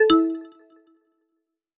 sos-beep-2.wav